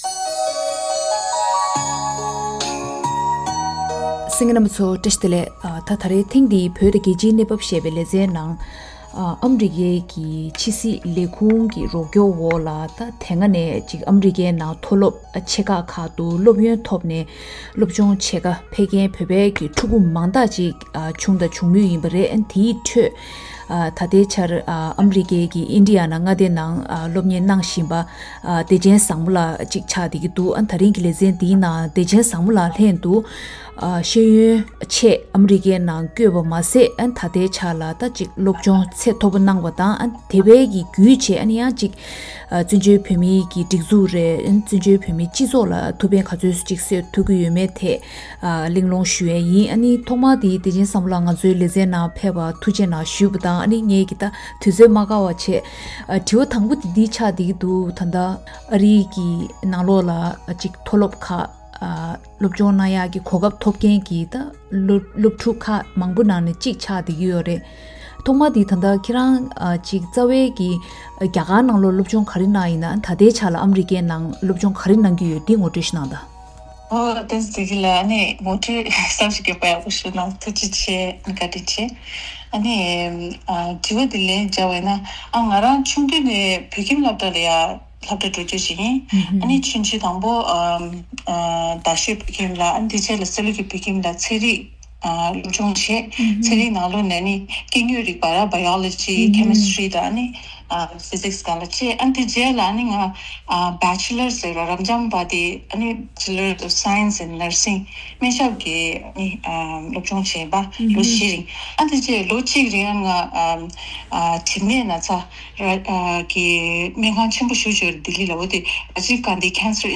ལྷན་གླེང་